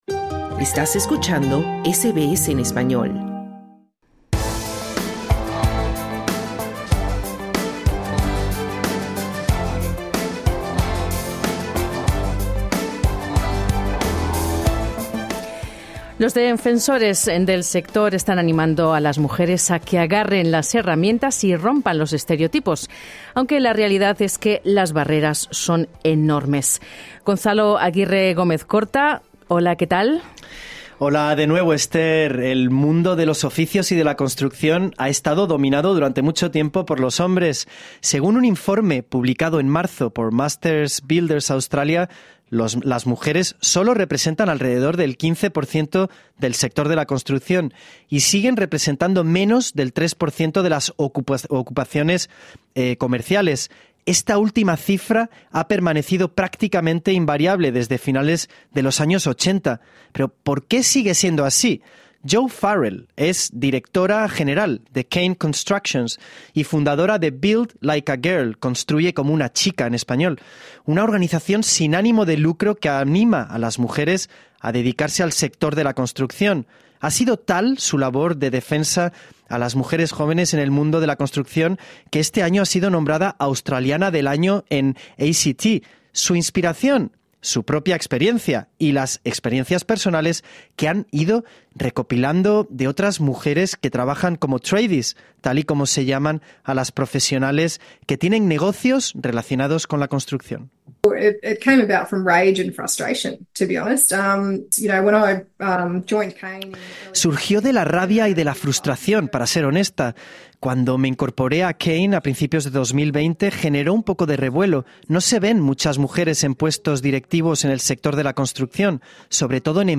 Hablamos con dos mujeres que quieren cambiar las cosas.